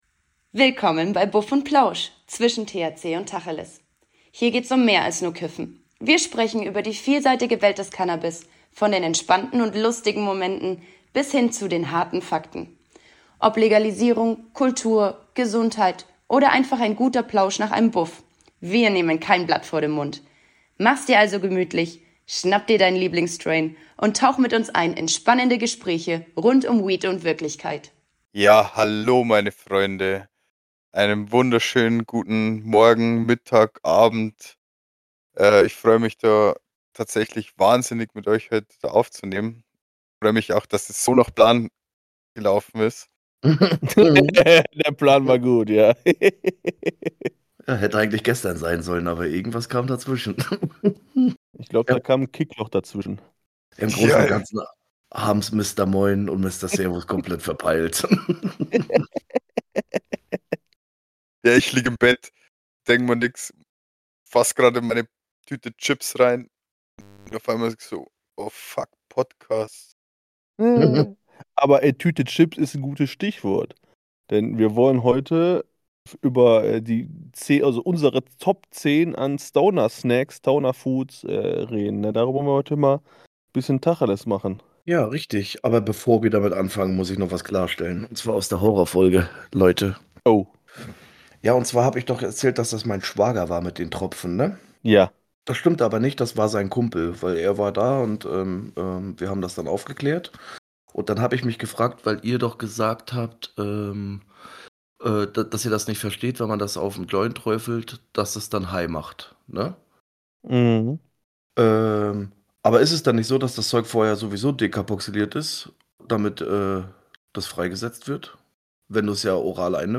Wir stellen euch unsere persönlichen Top 10 Stoner Foods vor, die garantiert für den ultimativen Genuss sorgen, wenn der Magen nach dem richtigen Kram schreit. Es wird viel gelacht, es gibt einige überraschende Platzierungen und ja, auch ein bisschen bayerisch wird nebenbei gelernt.